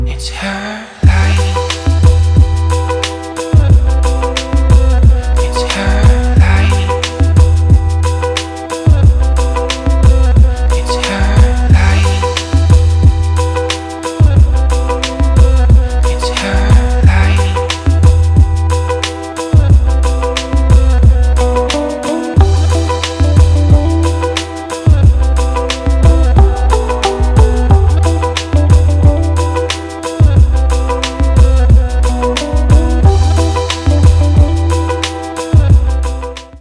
• Качество: 320, Stereo
поп
мужской вокал
Electronic
медлячок
Indie